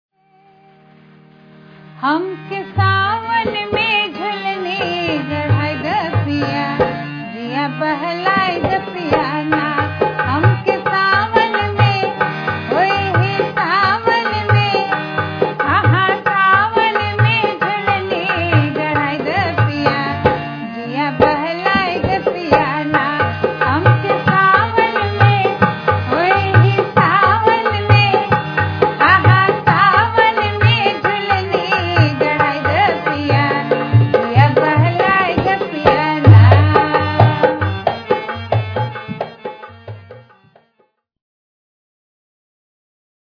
Bhojpuri, Awadhi
Kajri